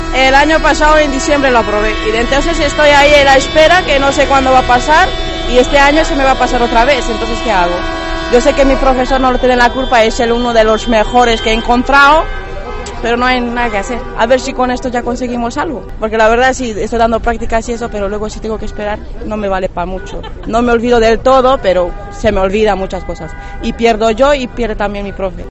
Alumna autoescuela